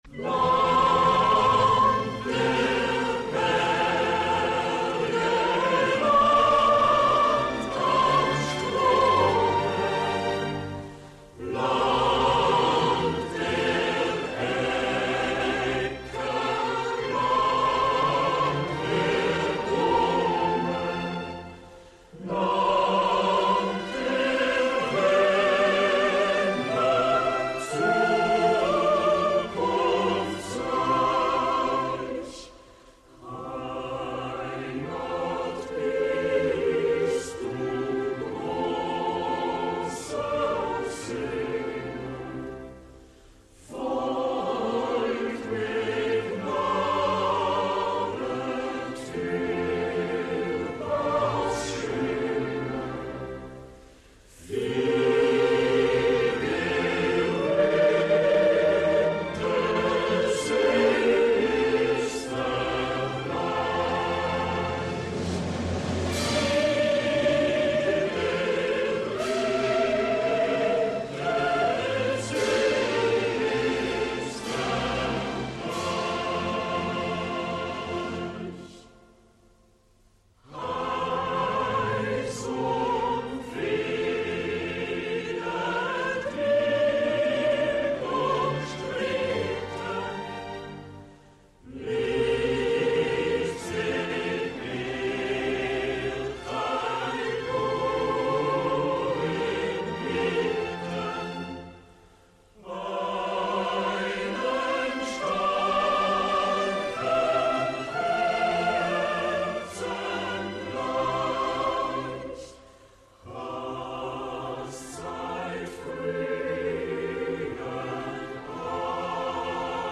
austria_anthem.mp3